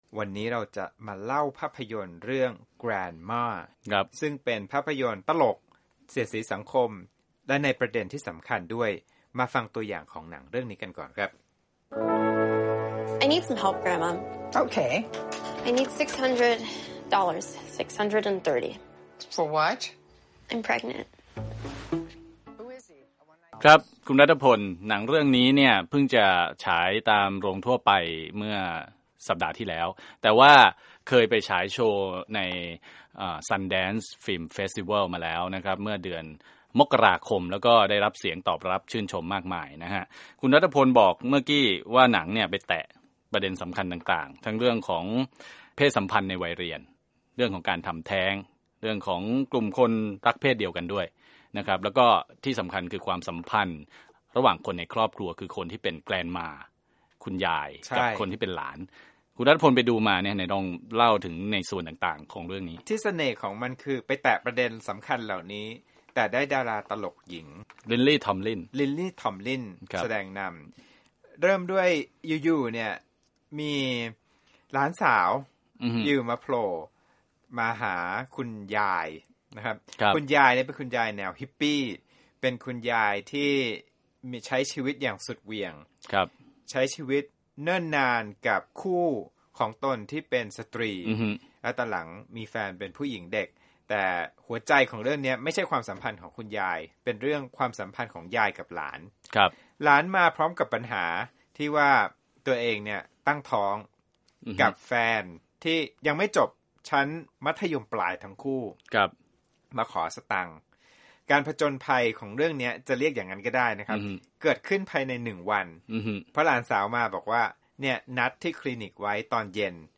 ร่วมกันคุยภาพยนตร์เรื่องนี้ให้ฟัง